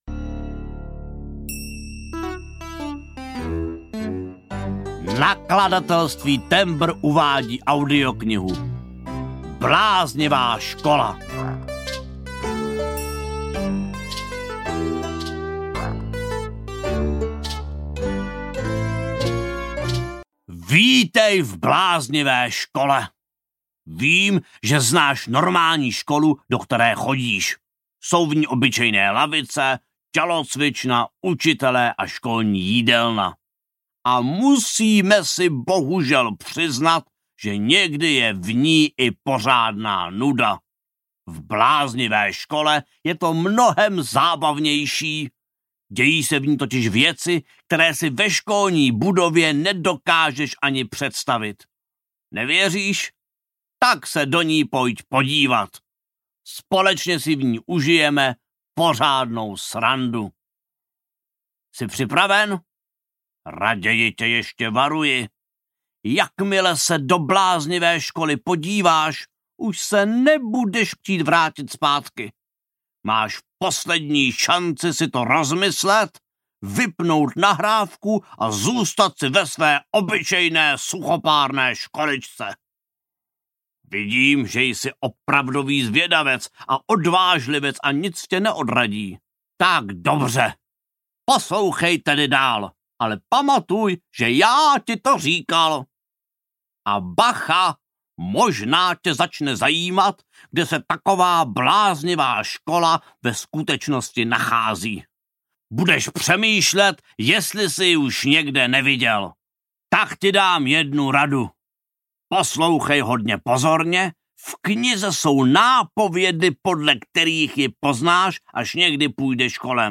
Bláznivá škola audiokniha
Ukázka z knihy